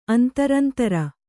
♪ antarantara